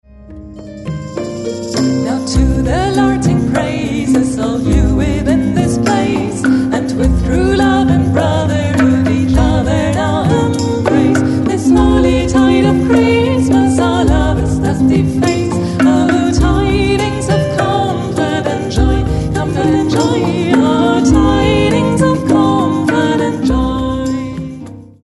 Advents- und Weihnachtsmusik